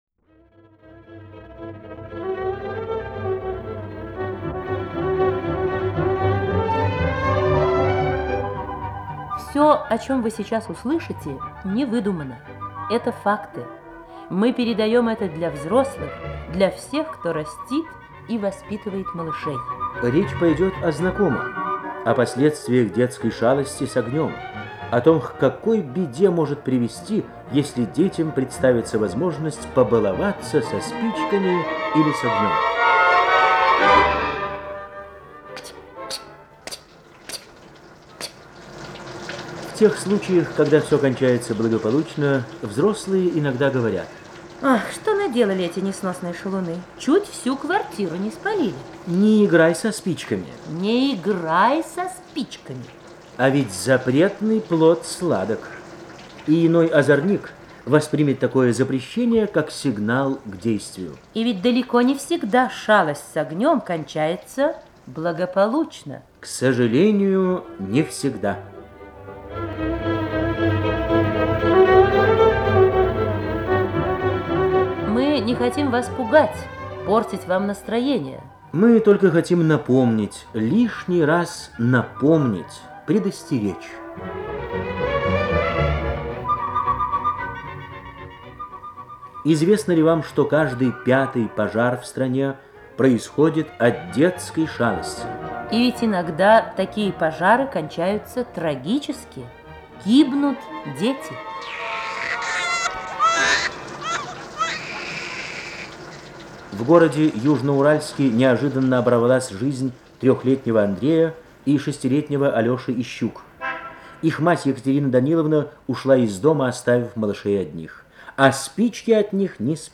Социальная реклама в СССР
Да,я тоже обратил внимание на музыку в конце-наверное,это начало 60-х